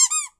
chuckle_death.ogg